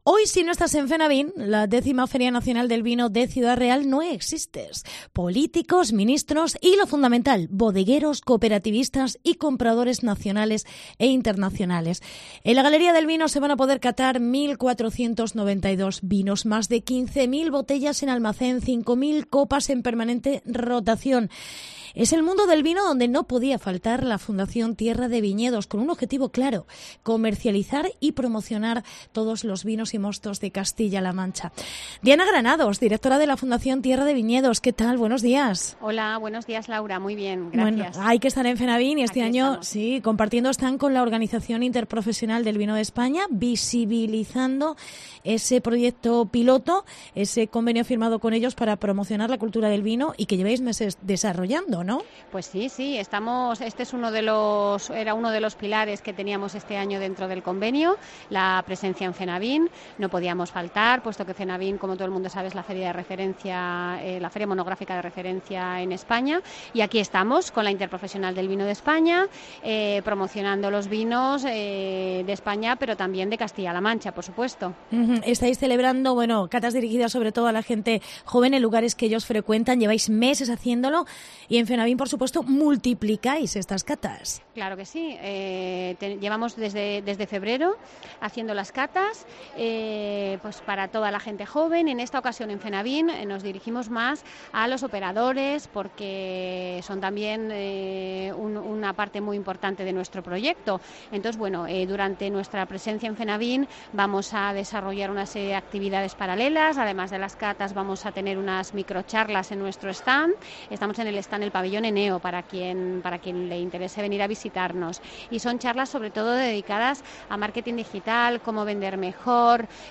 AUDIO: La Fundación Tierra de Viñedos en la X edición de FENAVIN. Entrevista